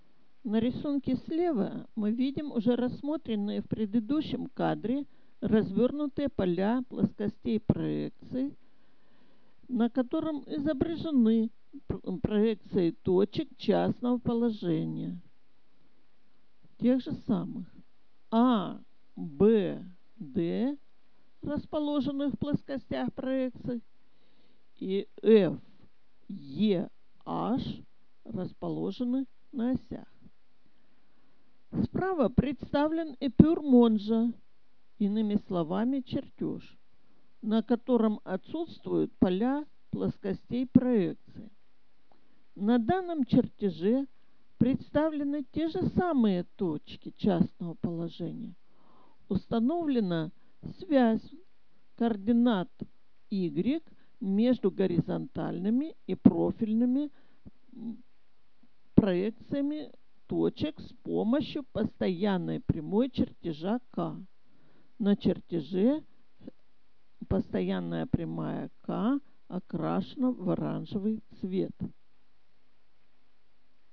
Аудио-комментарии